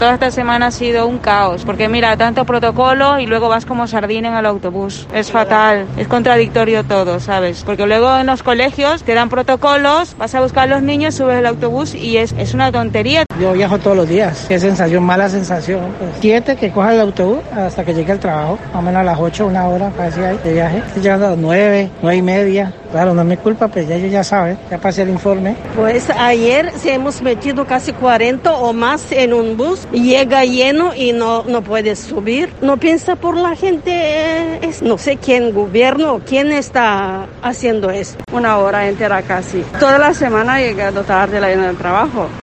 Usuarios de la EMT se quejan.